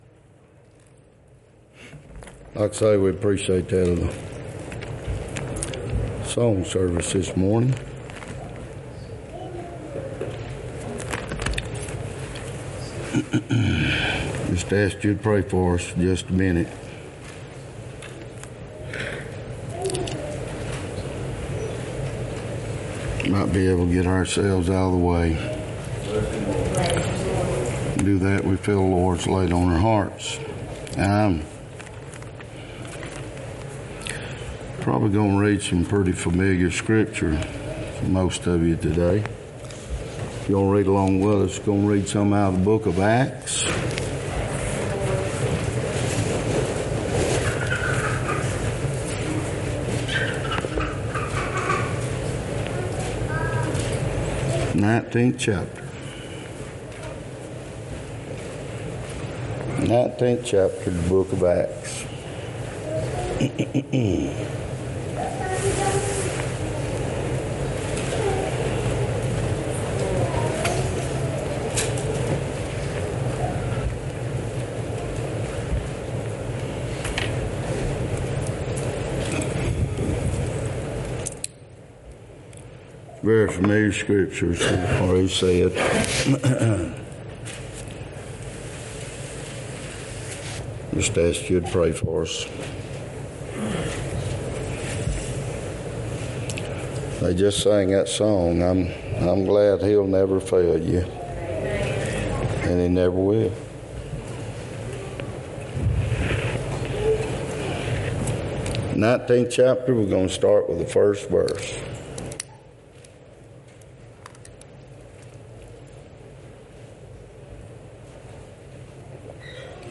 Acts 19:1-20 Service Type: Sunday Topics